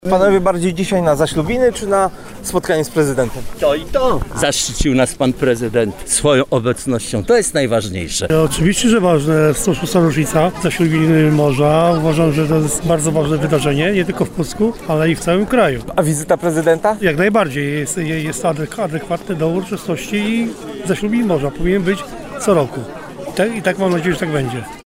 Prezydent Karol Nawrocki nie zabrał głosu w Pucku, ale spotkał się ze swoimi wyborcami, których do Pucka przyjechało kilkuset z całego Pomorza: